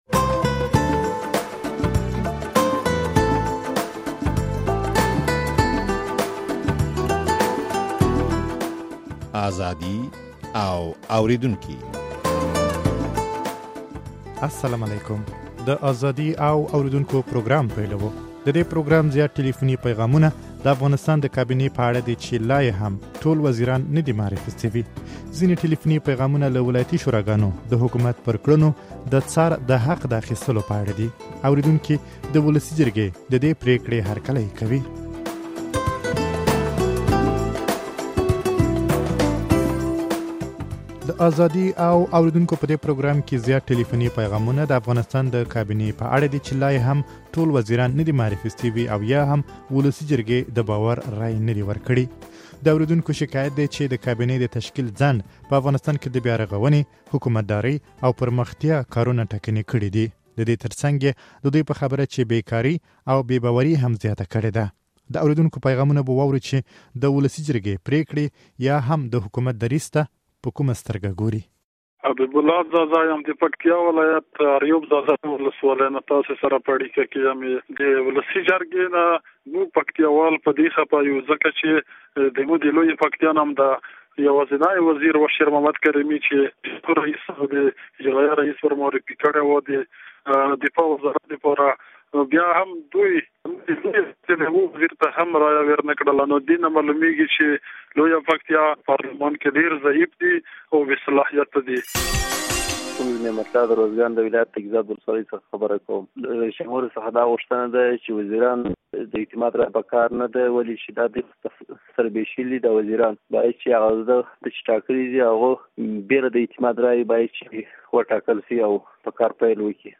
د دې پروګرام زيات ټليفوني پيغامونه د افغانستان د کابينې په اړه دي، چې لا يې هم ټول وزيران نه دي معرفي شوي.